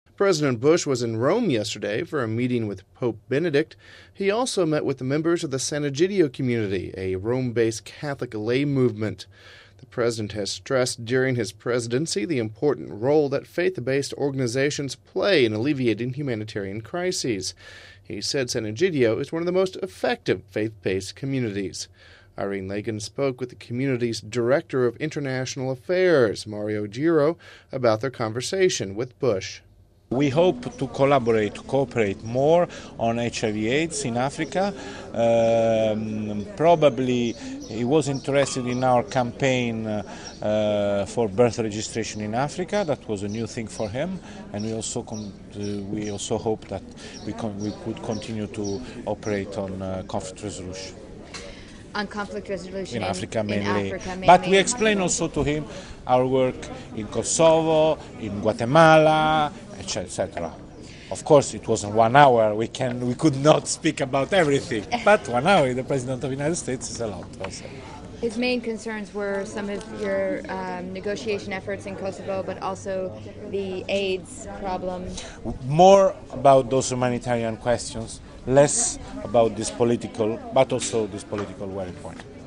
Home Archivio 2007-06-10 16:38:09 U.S. President Meets Sant'Egidio Movement (10 Jun 07 - RV) United States President George W. Bush met with members of the Rome-based Sant'Egidio Movement on Saturday. We have this report...